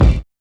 kits/RZA/Kicks/WTC_kYk (76).wav at main